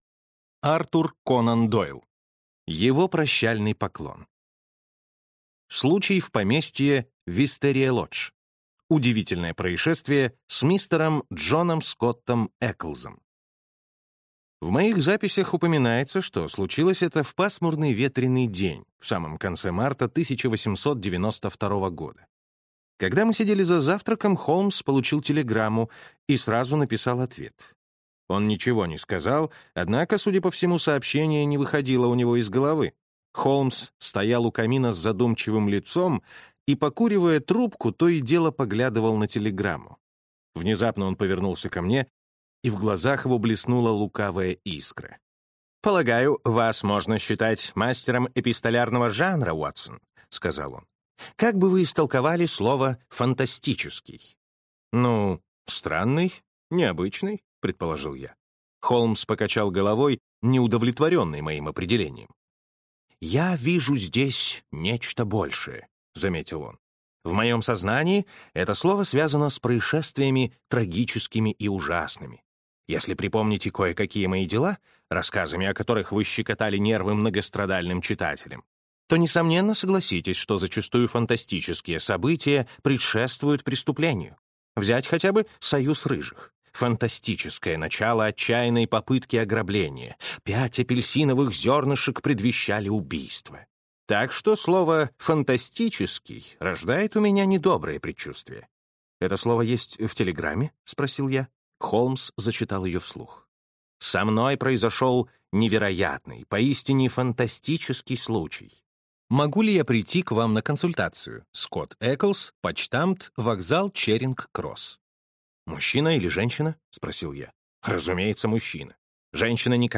Аудиокнига Его прощальный поклон. Архив Шерлока Холмса | Библиотека аудиокниг